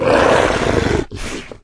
pdog_death_0.ogg